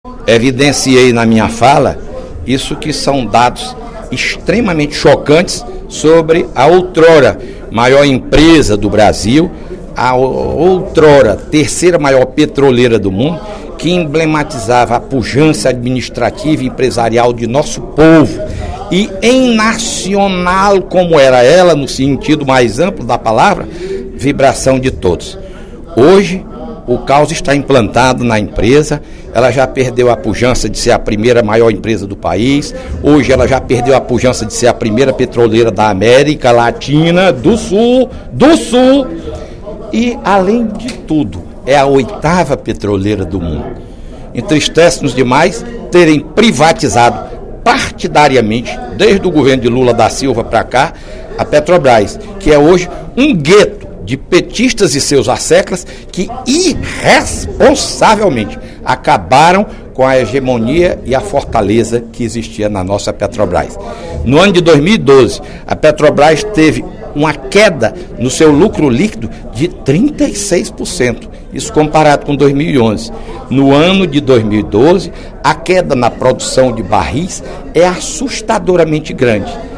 O deputado Fernando Hugo (PSDB) afirmou, durante o primeiro expediente desta quarta-feira (13/03), que o caos está instalado na Petrobras.